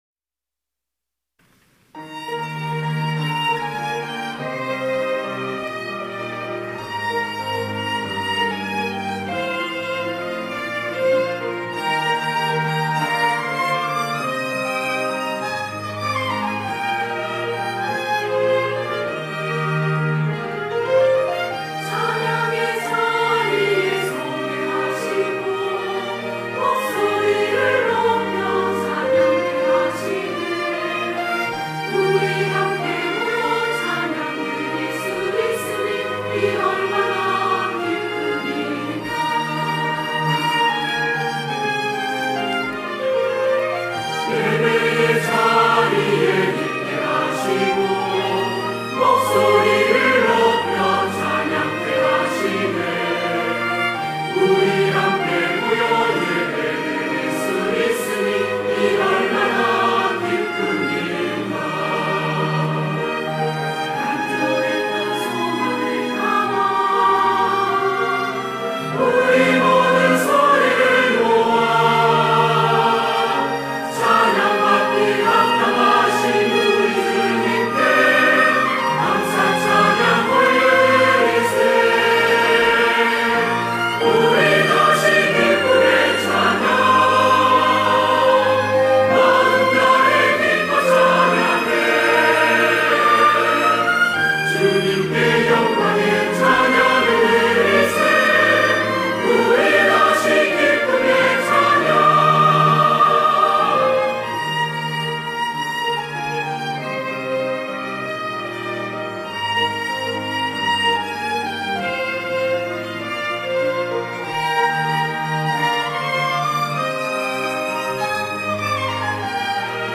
할렐루야(주일2부) - 우리 다시 기쁨의 찬양
찬양대